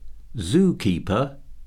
Escolta com es pronuncia nom